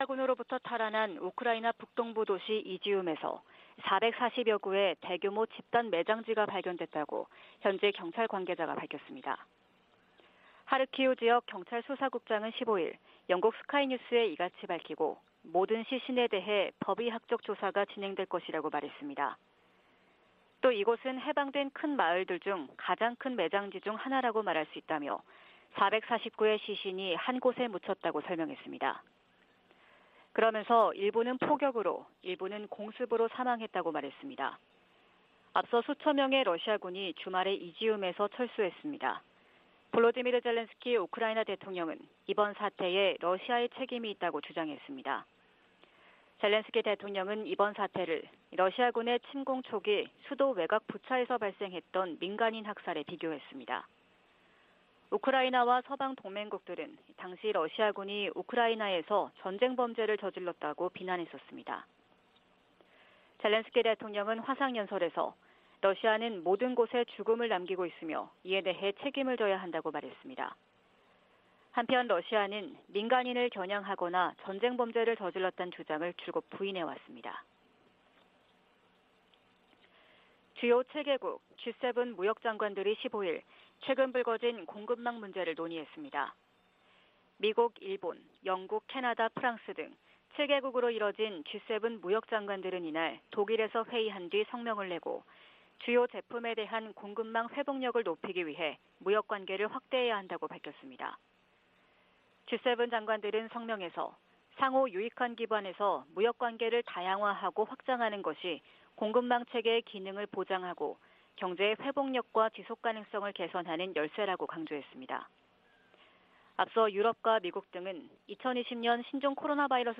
VOA 한국어 '출발 뉴스 쇼', 2022년 9월 17일 방송입니다. 리잔수 중국 전국인민대표대회 상무위원장은 북핵 문제에 관해 한반도 평화체제 구축이 당사국들의 이익에 부합한다는 원칙을 거듭 밝혔습니다. 미 국무부는 미한 확장억제전략협의체 회의에서 북한 위협에 맞서 협력을 확대하는 방안이 논의될 것이라고 밝혔습니다. 미국 전략사령관 지명자가 북한 핵 미사일 위협에 맞선 차세대 요격 미사일(NGI) 개발을 지지한다고 말했습니다.